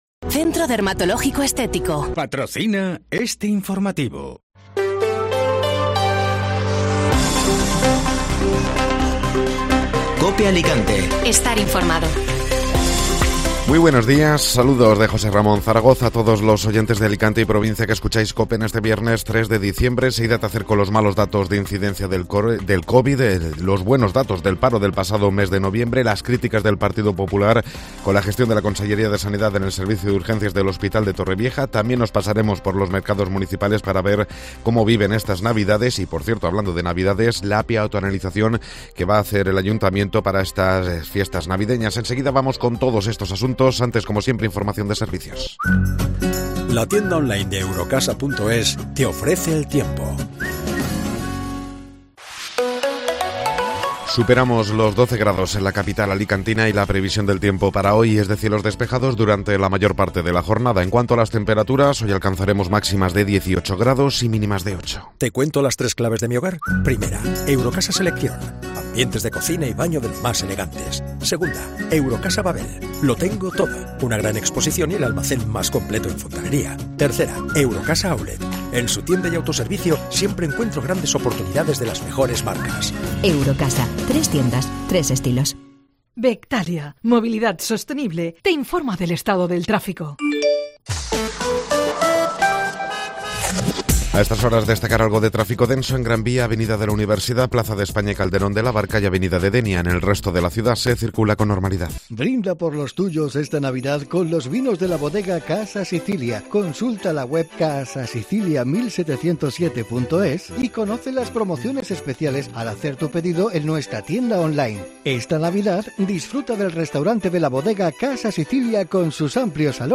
Informativo Matinal (Viernes 3 de Diciembre)